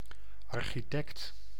Ääntäminen
Synonyymit bouwmeester Ääntäminen : IPA: [aɾ.ʃi.tɛkt] Tuntematon aksentti: IPA: /ɑrxiˈtɛkt/ Haettu sana löytyi näillä lähdekielillä: hollanti Käännös 1. arquitecto 2. arquitecta {f} Suku: m .